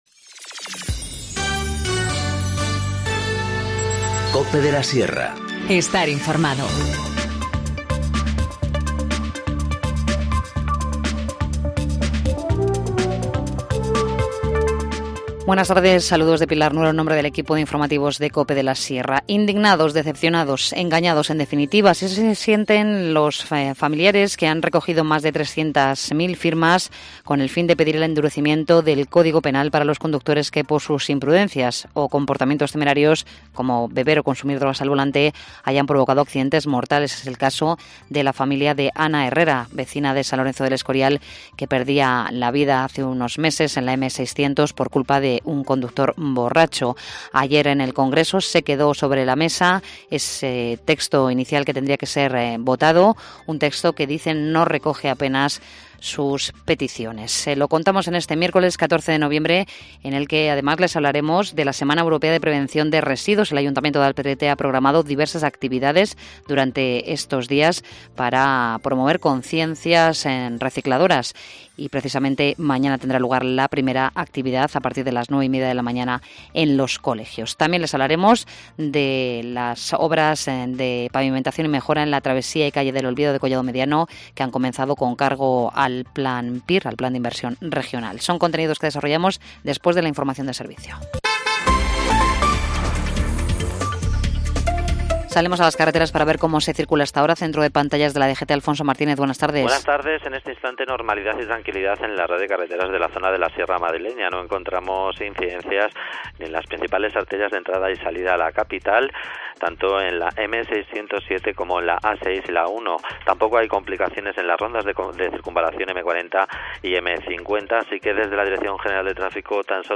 Informativo Mediodía 14 nov- 14:20h